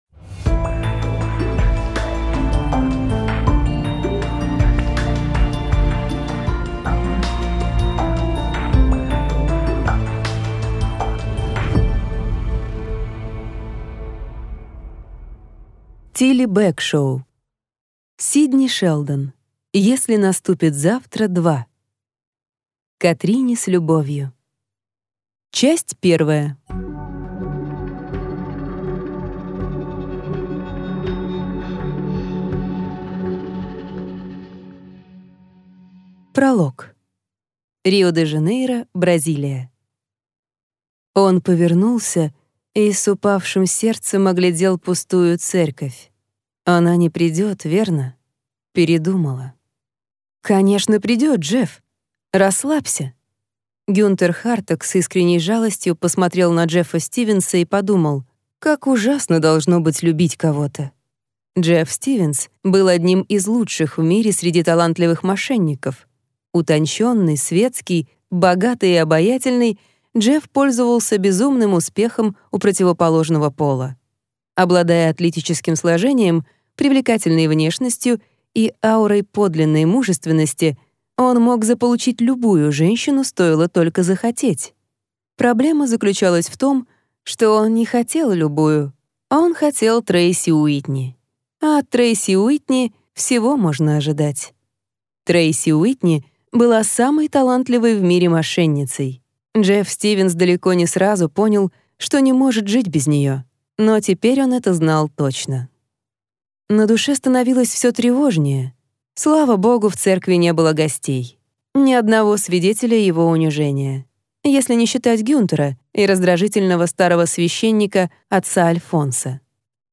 Аудиокнига Сидни Шелдон. Если наступит завтра – 2 | Библиотека аудиокниг